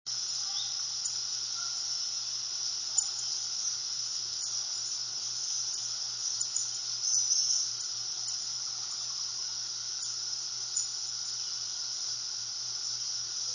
Field Sparrow
Field Sparrow Field Sparrow song, Appalachian Trail, South Ridge, Delaware Water Gap, NJ (pink head) 4/21/01 (8kb) wave376A Field Sparrows "peeping", road to Indian Head Point, 8/19/03, 7:45 a.m.(53kb) wave783 Index
sparrow_field_peeps_783.wav